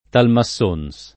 Talmassons [ talma SS1 n S ] top. (Friuli)